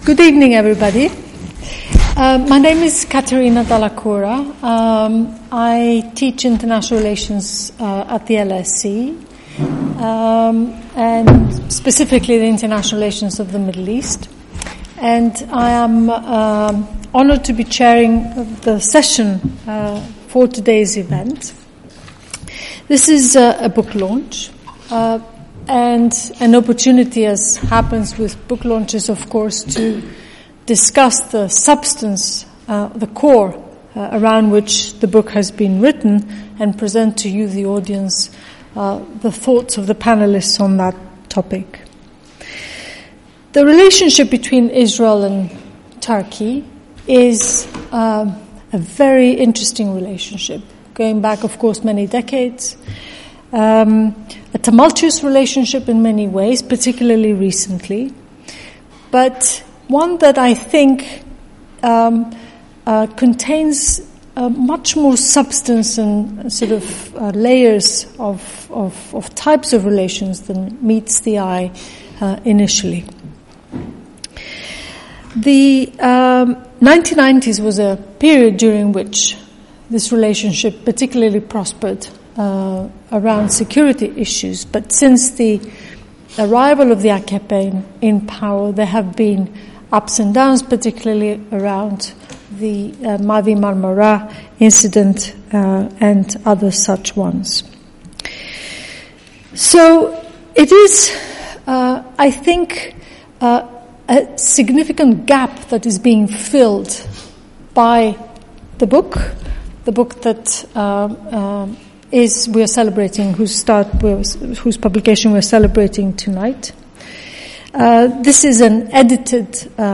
The event introduced a multi-dimensional comparative perspective on the Israeli-Turkish relations and provides comparative analyses from both Israeli and Turkish contributors. The panel will focus on the issues of state politics and ideology, leadership nationhood, economy, tourism, regional politics and energy relations in Israel and Turkey.